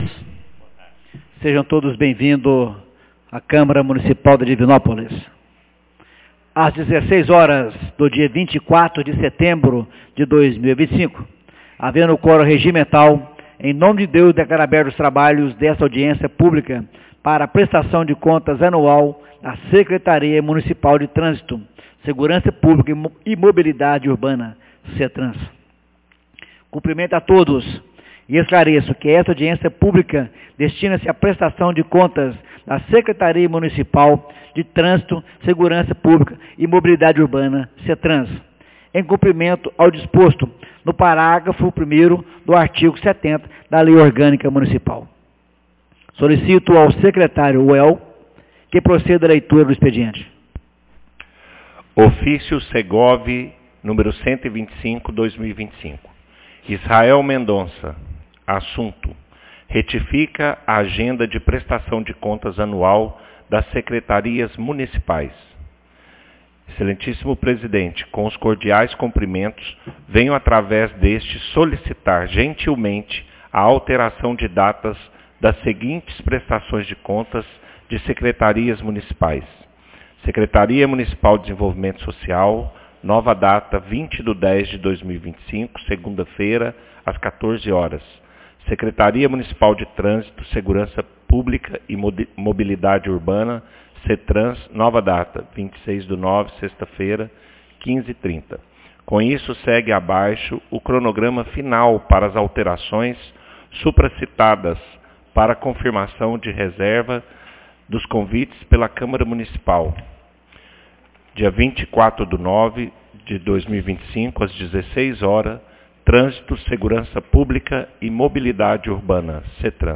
Audiencia publica Prestação de Contas Setrans 24 de setembro de 2025